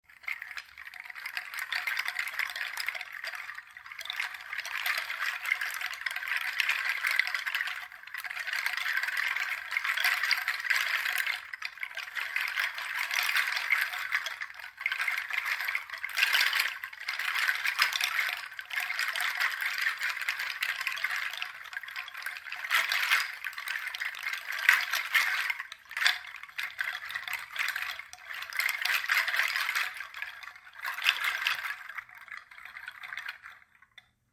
Seed Shaker Pangi
• Produces a warm sound
Pangi seeds are a beautiful way to add a relaxing rain effect to your events. Perfect for enhancing sound therapy sessions, and meditation, these instruments produce soft, warm sounds that add a dynamic layer to any auditory experience.
The natural seeds produce clear, resonant tones that add a unique texture to your music.
• Material: Made from authentic tropical, dried Pangi seeds for soft, resonant tones